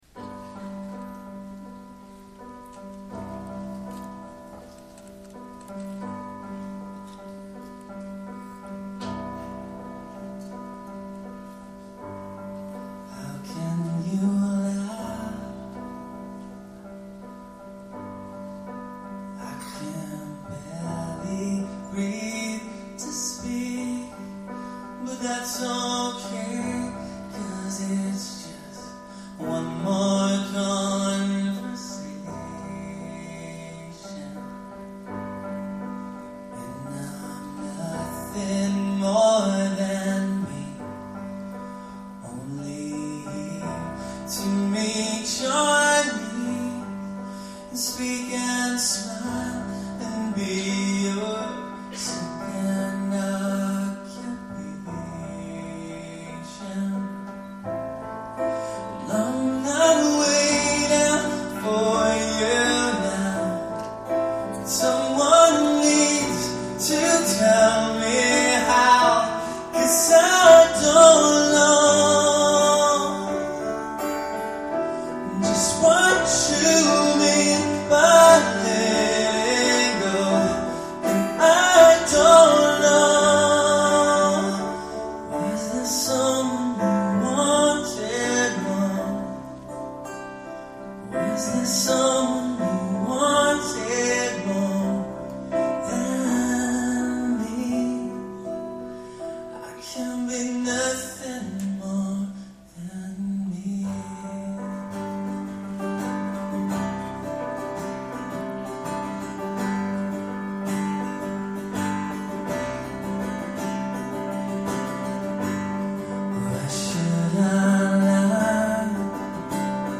Acoustic Set